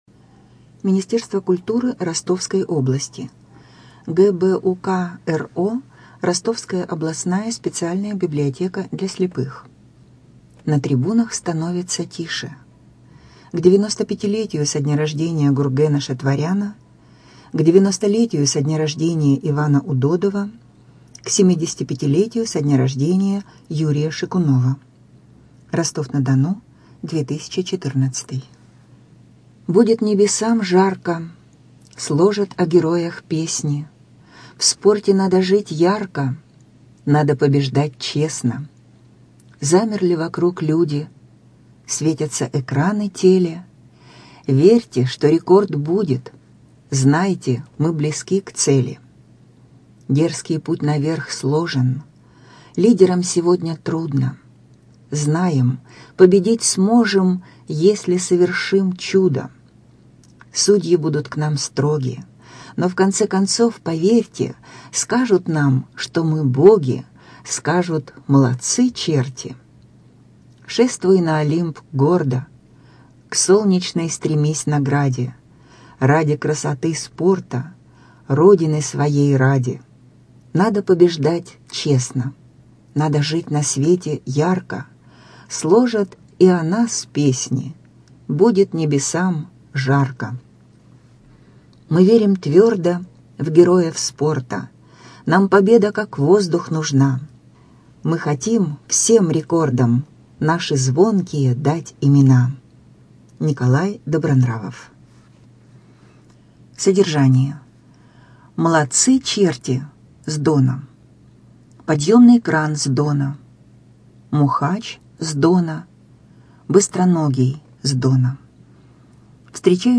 Студия звукозаписиРостовская областная библиотека для слепых